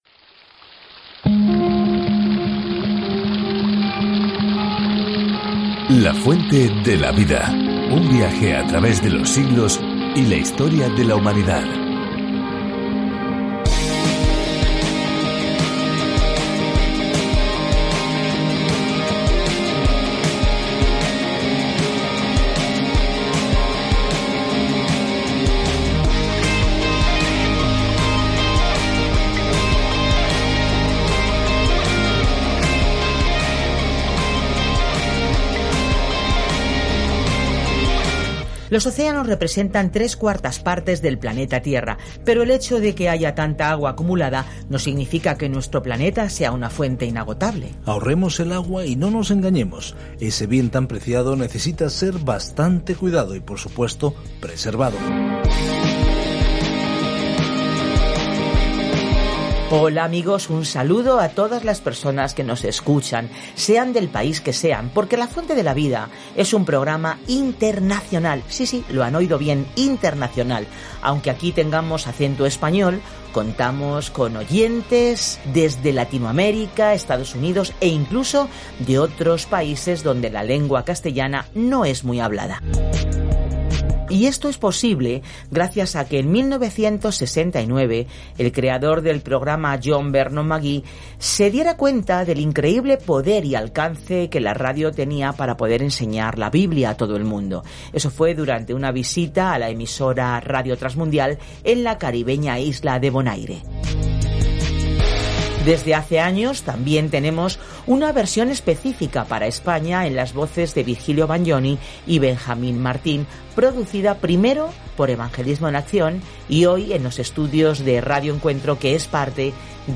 Escritura MATEO 17:1-27 MATEO 16:28 Día 26 Iniciar plan Día 28 Acerca de este Plan Mateo demuestra a los lectores judíos las buenas nuevas de que Jesús es su Mesías al mostrar cómo su vida y ministerio cumplieron la profecía del Antiguo Testamento. Viaje diariamente a través de Mateo mientras escucha el estudio de audio y lee versículos seleccionados de la palabra de Dios.